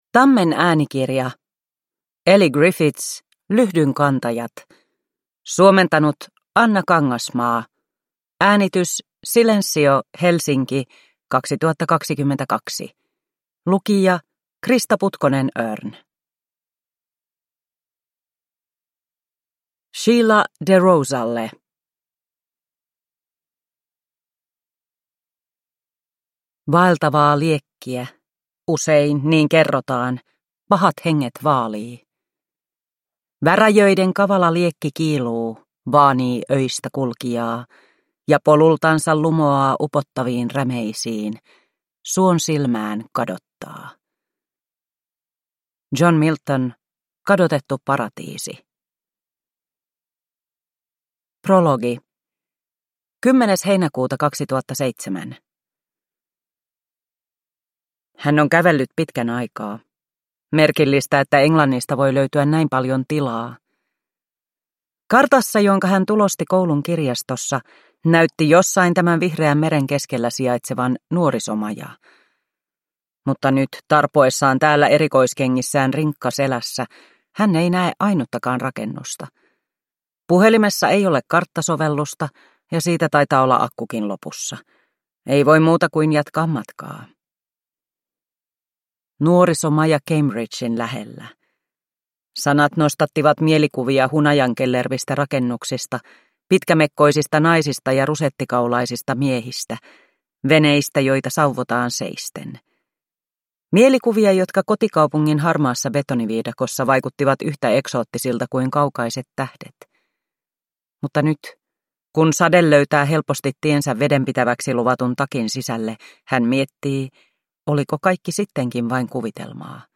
Lyhdynkantajat (ljudbok) av Elly Griffiths